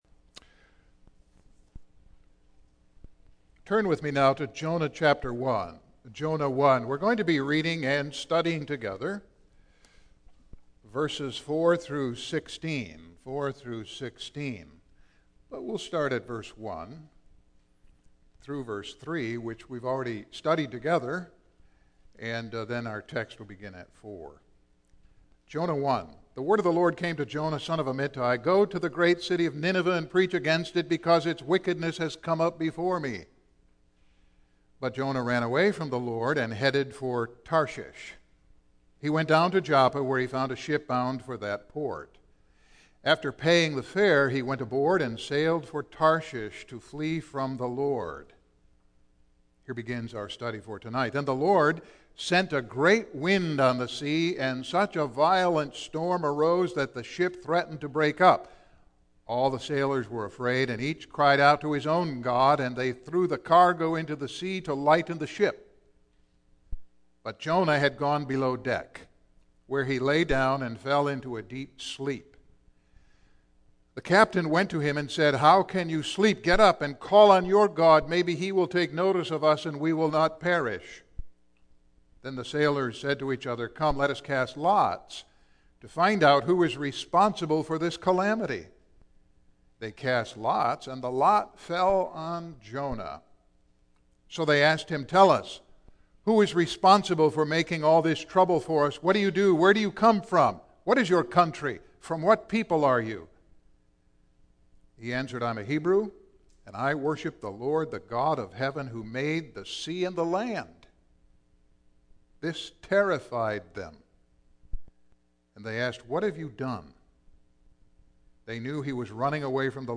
Series: Single Sermons Passage: Jonah 1:1-16